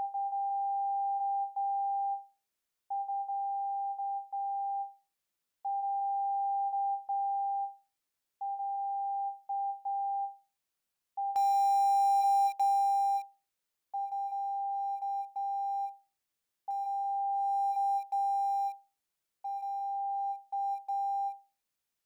Hornet Autogain Pro MK2 - Störgeräusche
Hornet Autogain Pro MK2 on Testtone_174bpm.wav Hornet Autogain Pro MK2 on Testtone_174bpm.wav 5,6 MB · Aufrufe: 373 Hornet Autogain Pro OLD on Testtone_174bpm.wav Hornet Autogain Pro OLD on Testtone_174bpm.wav 5,6 MB · Aufrufe: 379